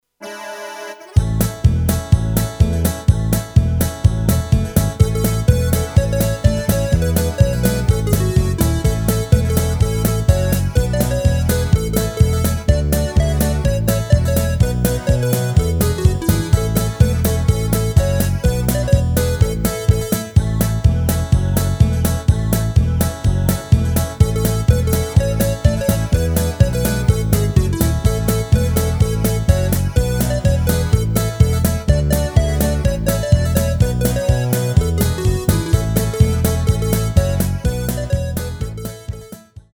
Rubrika: Národní, lidové, dechovka